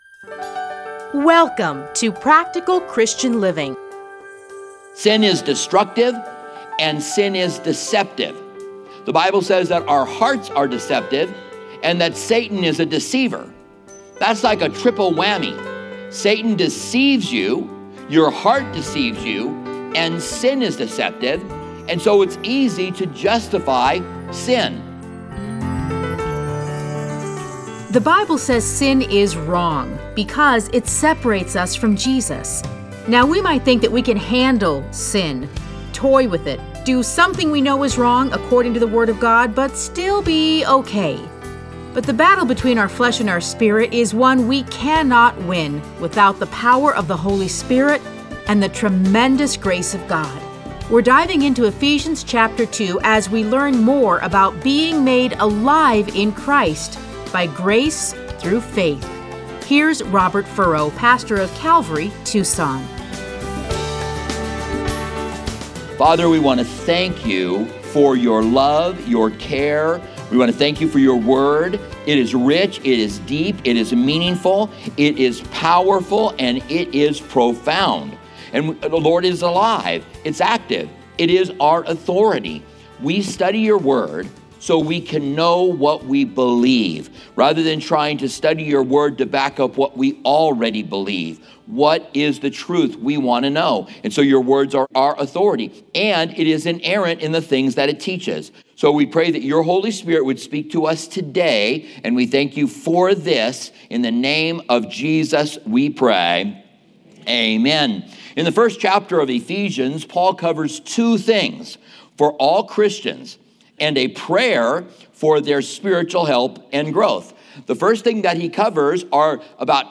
Listen to a teaching from Ephesians 2:1-9.